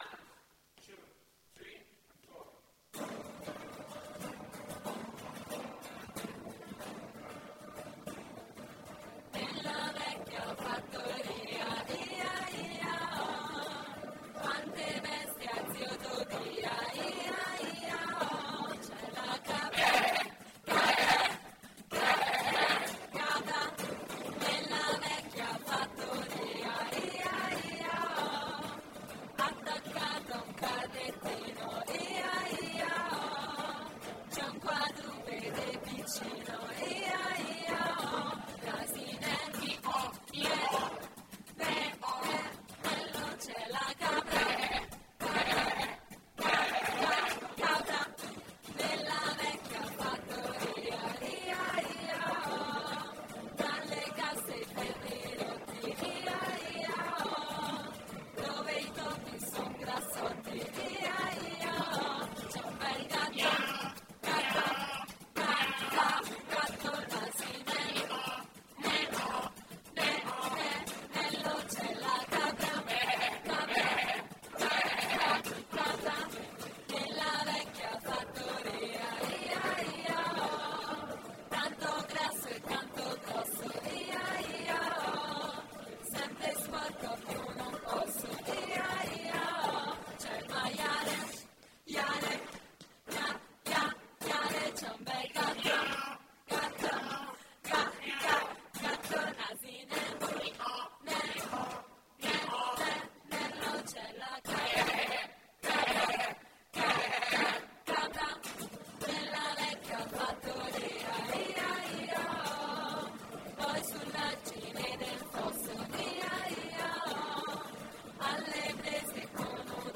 Cover del brano tradizionale per bambini
chitarra, banjo, voci ed effetti
in presa diretta